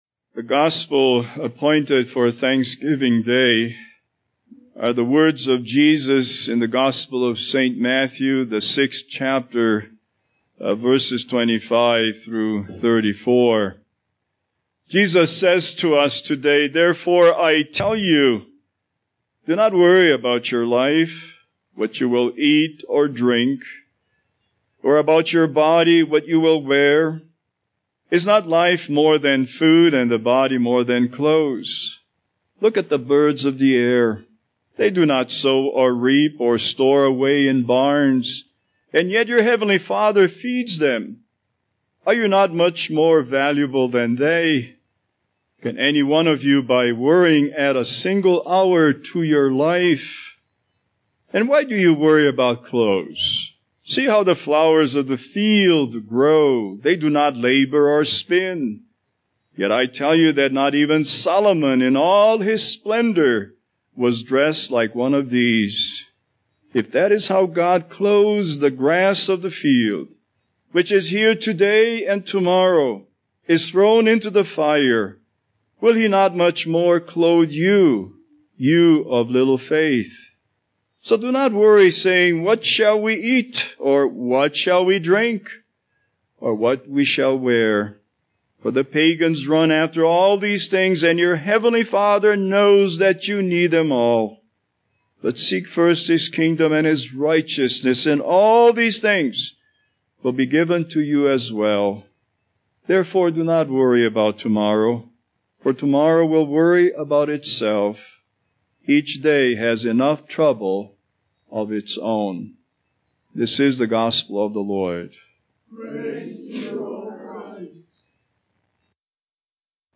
Series: Holiday Sermons
Matthew 6:25-34 Service Type: Thanksgiving Service